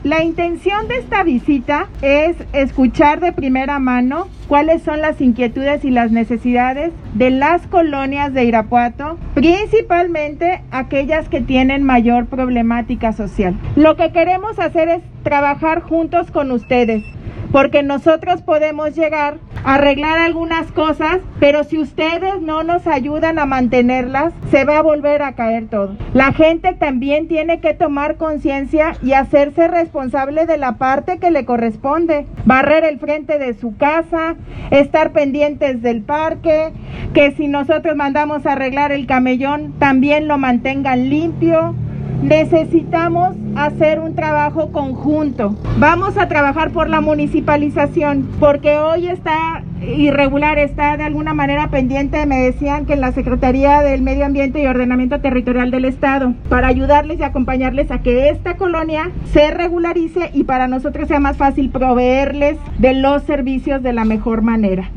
AudioBoletines
Lorena Alfaro García – Presidenta Municipal